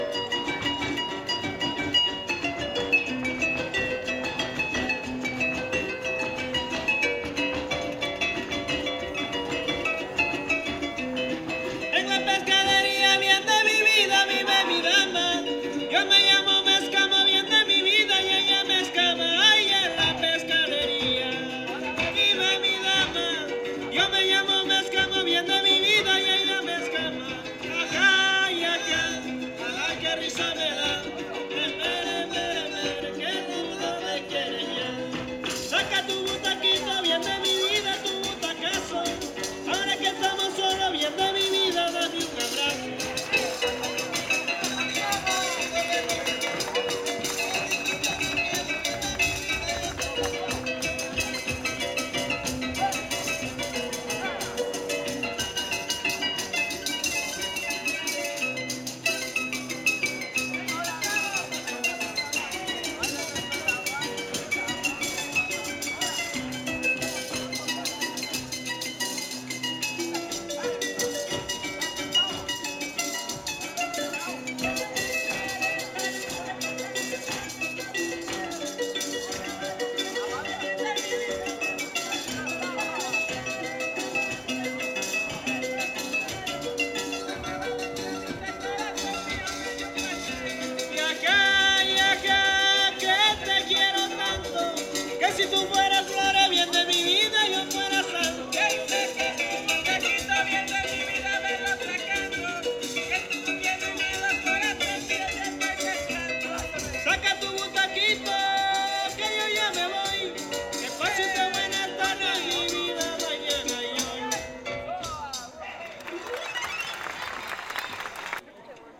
Concurso Estatal de Fandango